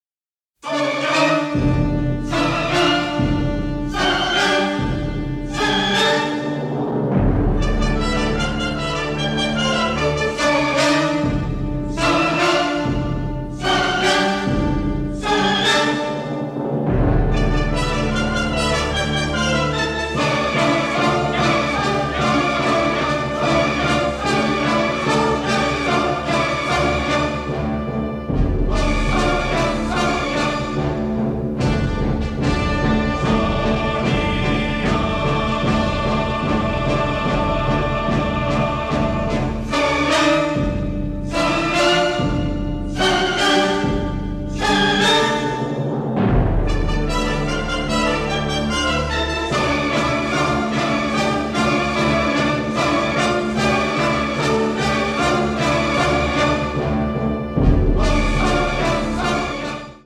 THE FILM SCORE (MONO)